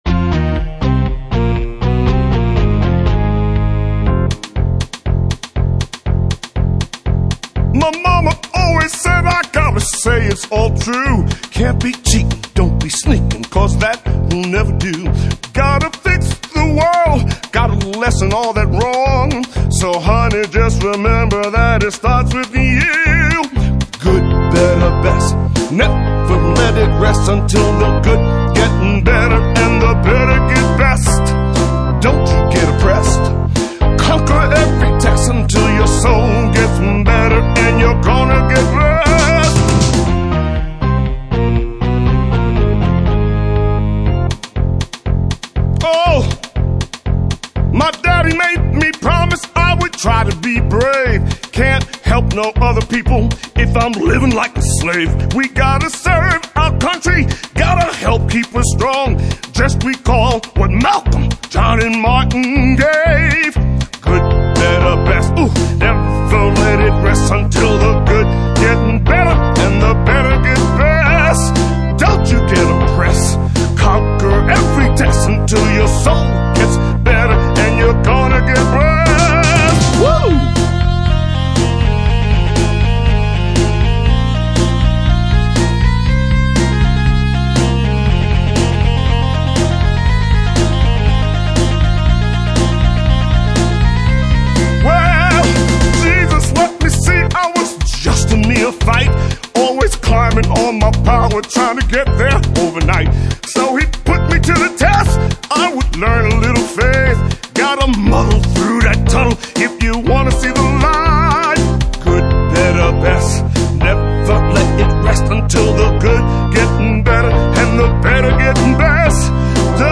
approx song time 2:58   Vocal M. Funk Rock with soul.